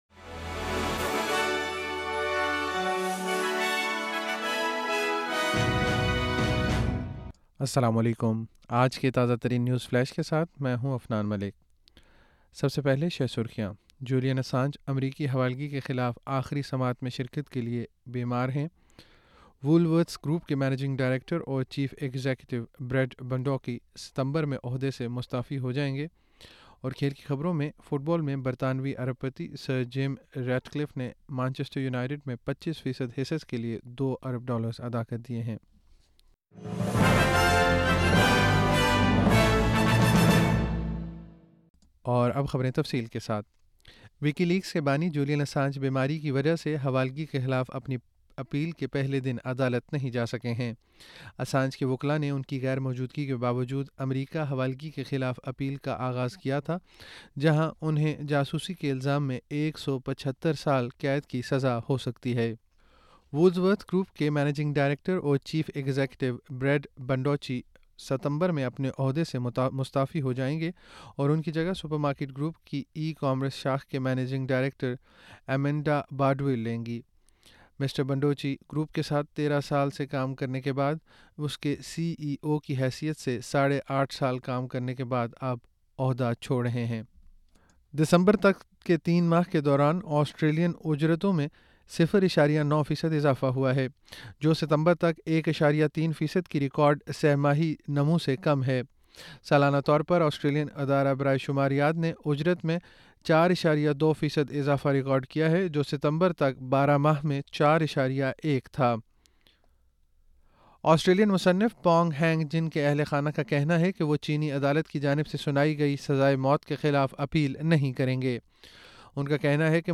نیوز فلیش 21 فروری 2024: جولین اسانج امریکی حوالگی کے خلاف آخری سماعت میں شرکت کے لیے 'بیمار'